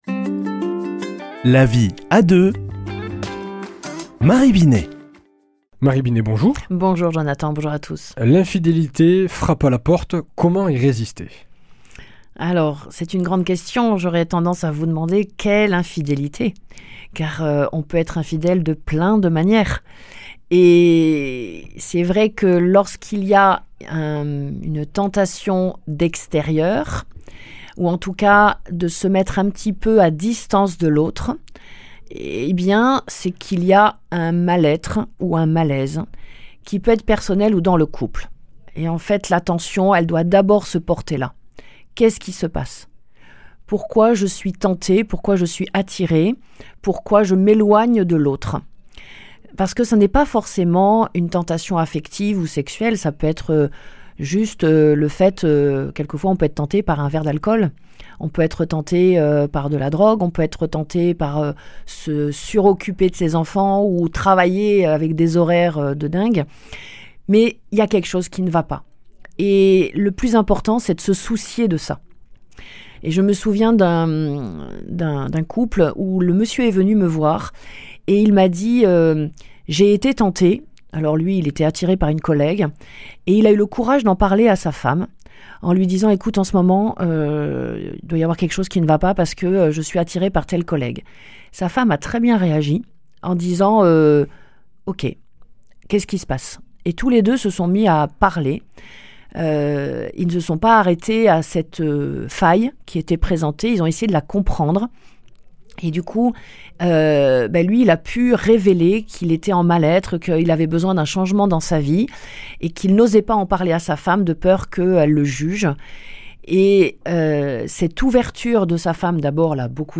mardi 11 mars 2025 Chronique La vie à deux Durée 4 min